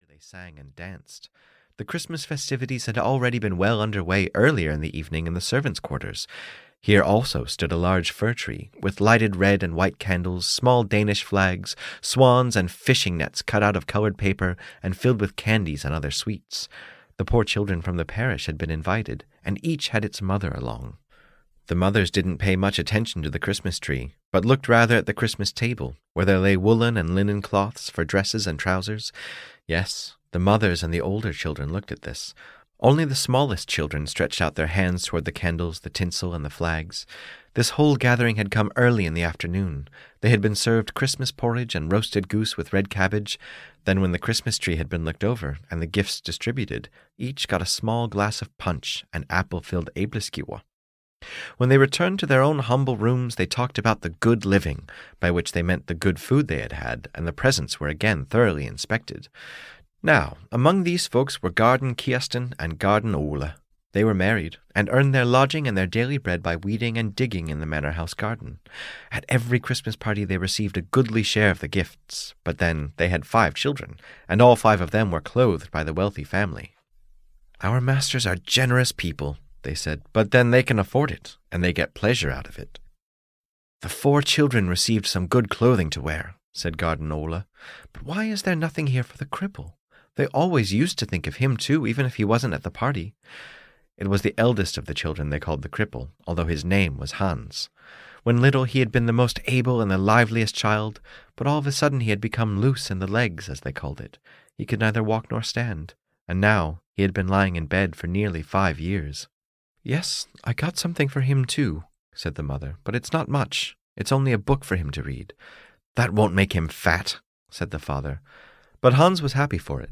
The Cripple (EN) audiokniha
Ukázka z knihy